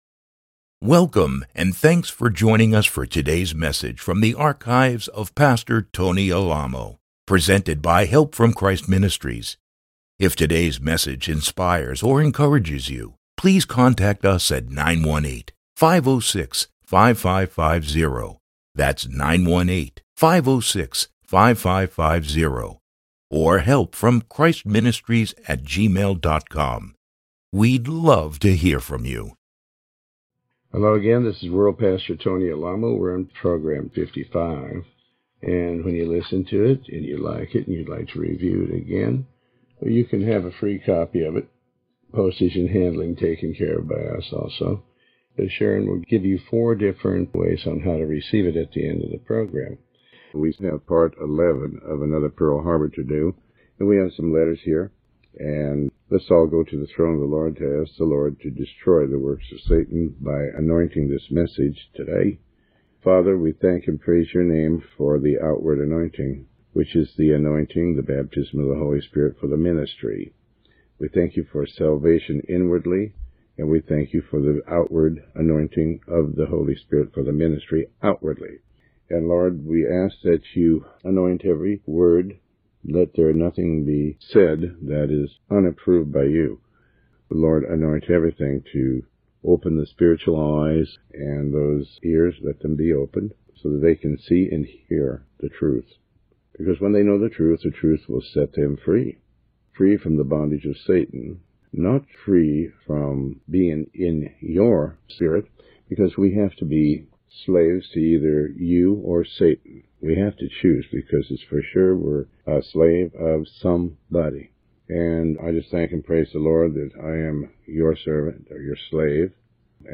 Sermon 55A